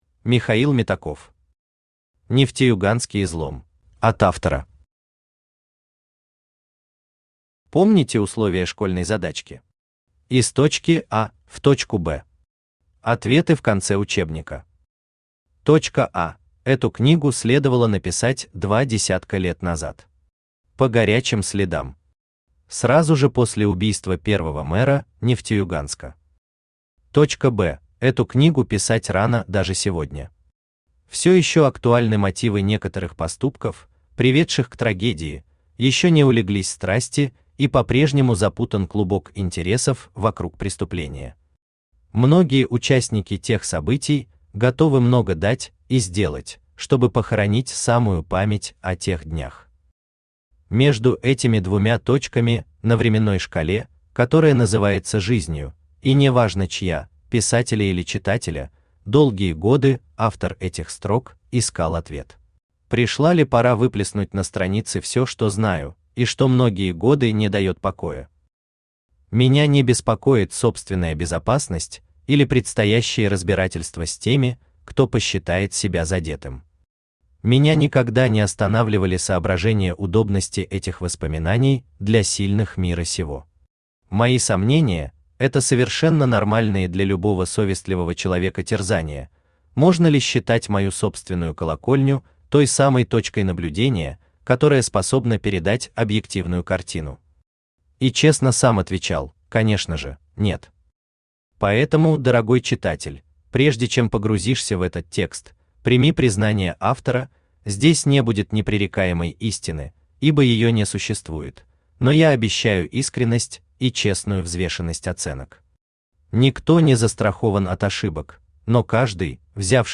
Aудиокнига Нефтеюганский излом Автор Михаил Митрофанович Метаков Читает аудиокнигу Авточтец ЛитРес.